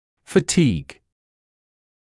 [fə’tiːg][фэ’тиːг]усталость, утомление